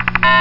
buttons1.mp3